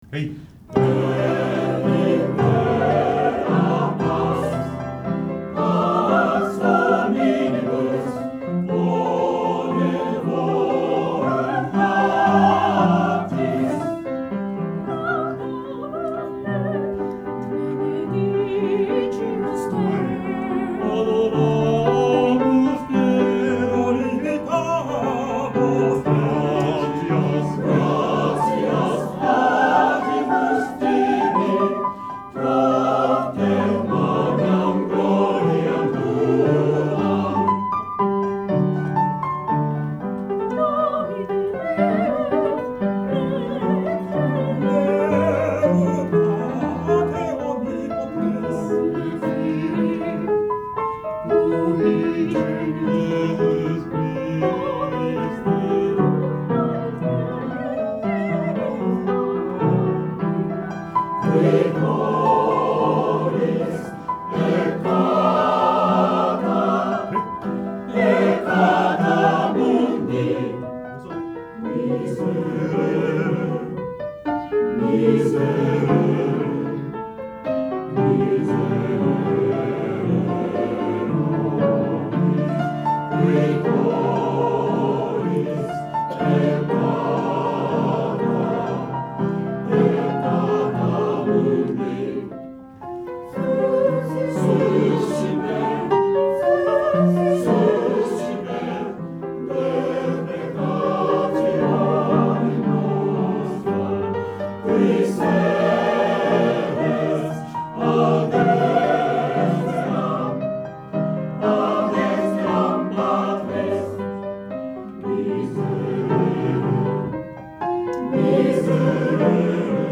練習場所：アスピア明石北館　8階学習室801A・B（明石市）
出席者：31名（sop13、alt8、ten5、bass5）
・Gloria　♪=108で　46～49小節/74～77小節の「miserere」気持ちを入れる　84小節のsoloテンポ少し落とす　→通し